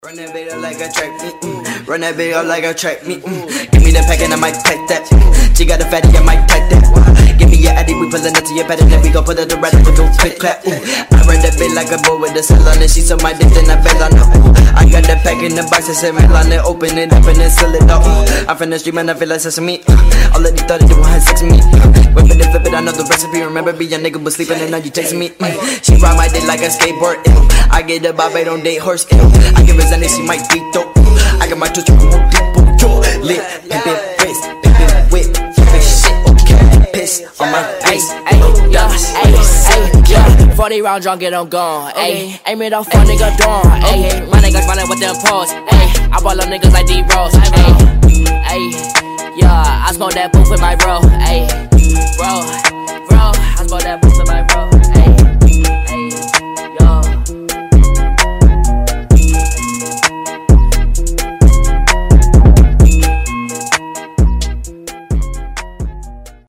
• Качество: 320, Stereo
громкие
качающие